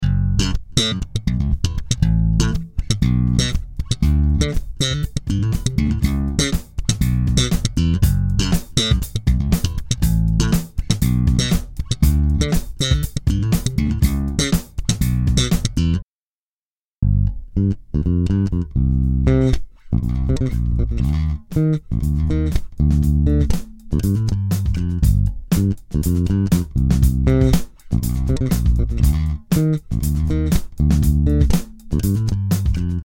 WARMOTH JAZZ BASS 5 activ/passiv (usa 2011)